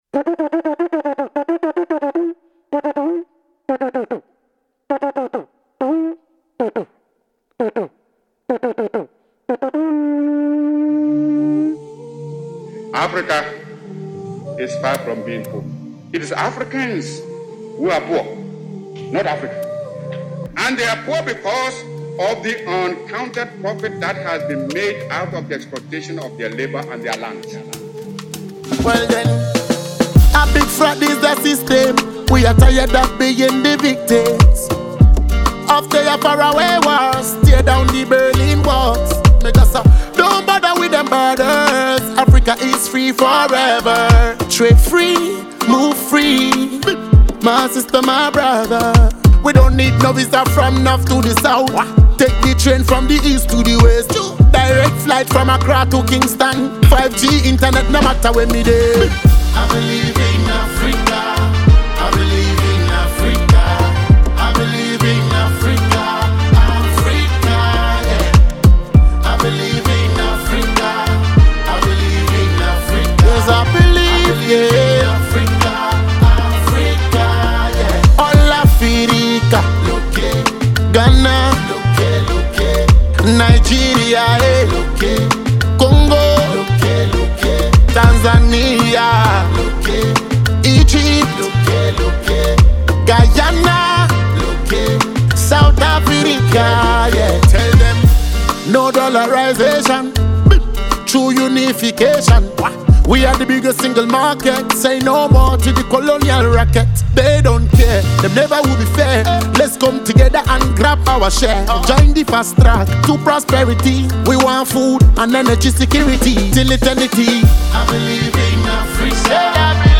Award-winning Ghanaian Afro-dancehall artist